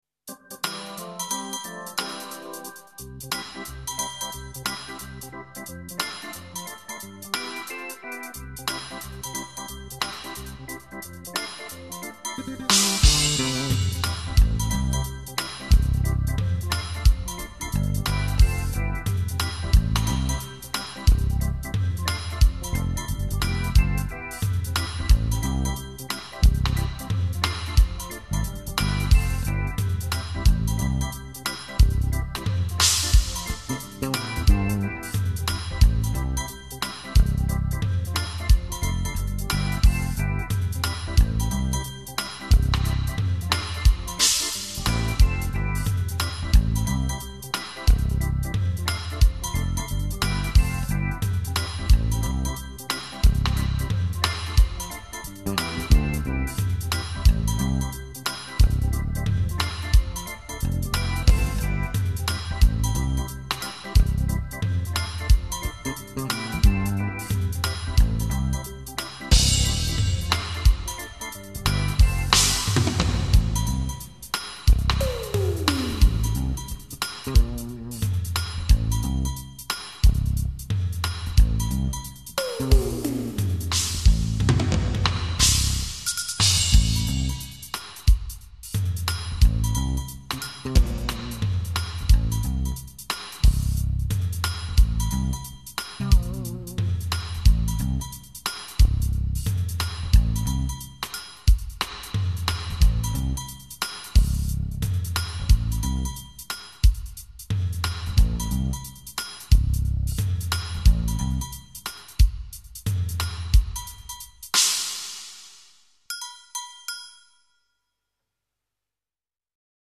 影片中的配乐十分的好听，总让人想起童年时坐过的旋转木马。